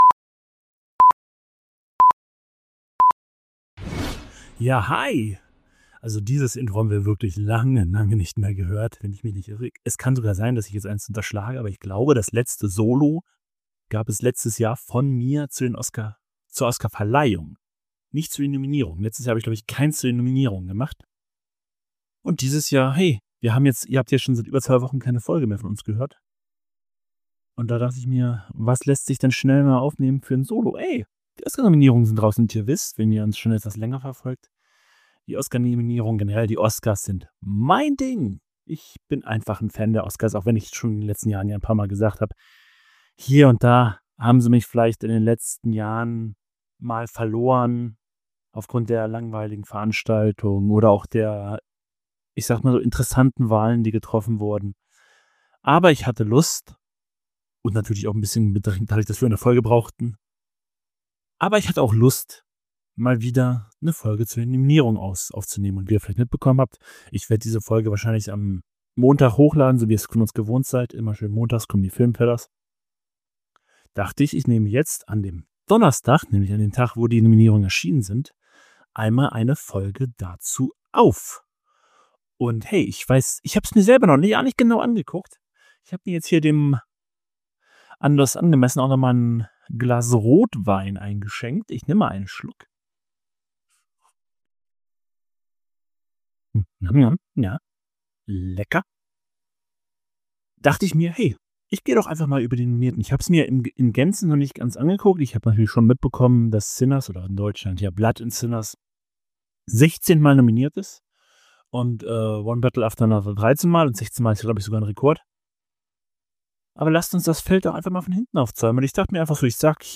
Mit erfreulich positiver Stimmung spricht er über zwei große Favoriten, weitere spannende Titel – und einige Filme, die direkt auf seiner Watchlist landen.